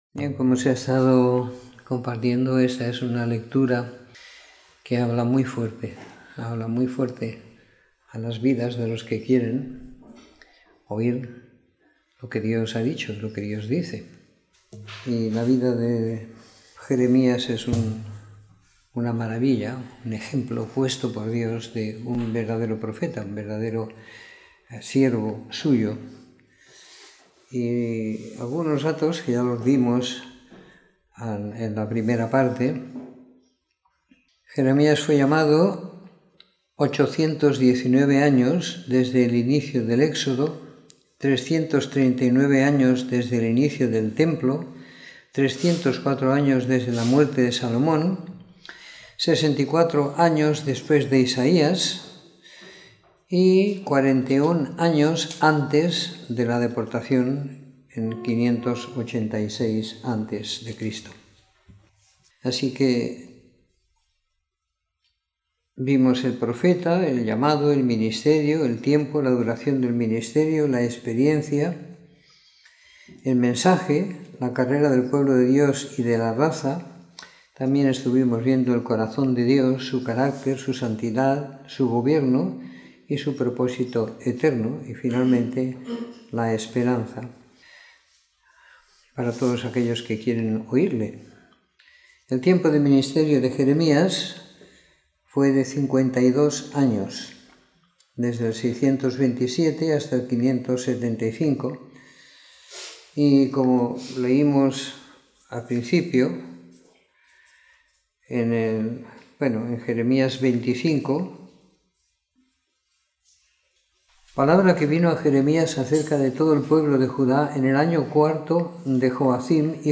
Comentario en Jeremías 31-52 - 26 de Octubre de 2018
Comentario en el libro de Jeremías a partir del capitulo 31 siguiendo la lectura programada para cada semana del año que tenemos en la congregación en Sant Pere de Ribes.